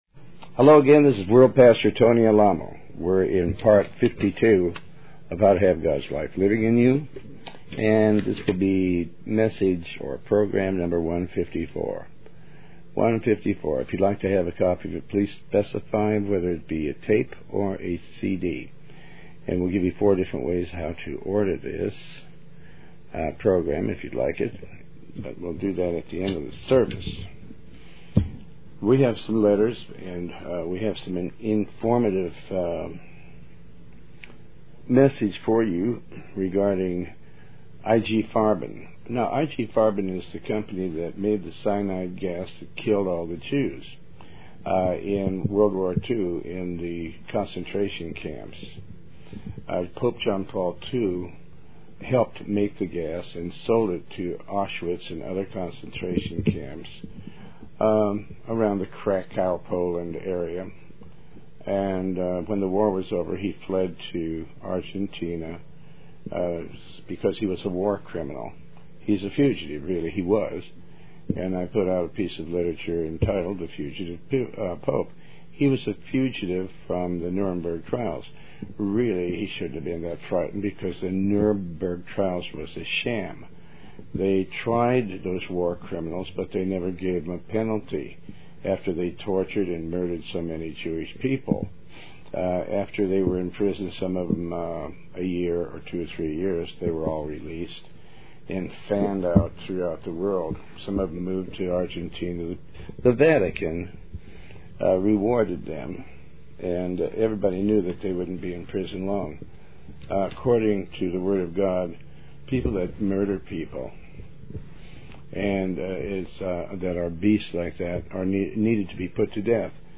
Bible Study
Show Host Pastor Tony Alamo